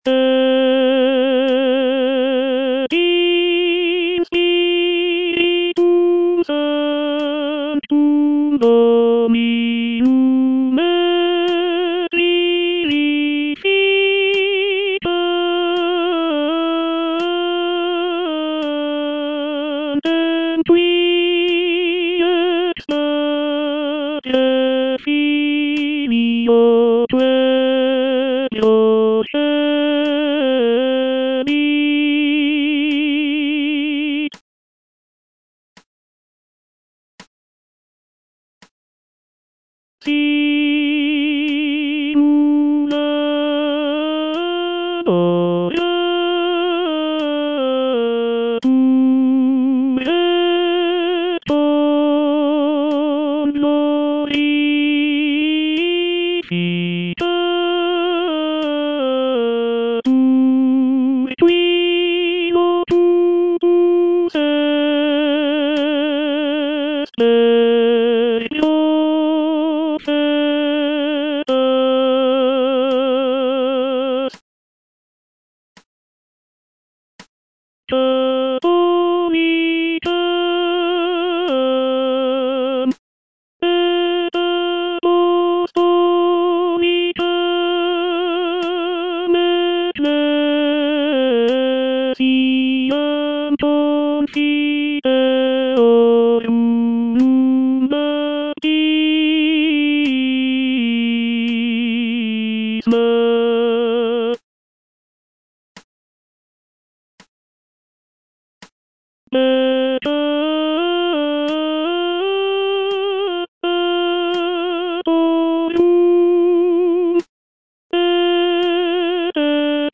G.P. DA PALESTRINA - MISSA TU ES PETRUS (A 6) Et in Spiritum Sanctum - Tenor (Voice with metronome) Ads stop:
"Missa Tu es Petrus" is a six-part polyphonic choral composition by Giovanni Pierluigi da Palestrina, a renowned Italian Renaissance composer. The work is a setting of the Ordinary of the Catholic Mass, specifically the Kyrie, Gloria, Credo, Sanctus, Benedictus, and Agnus Dei.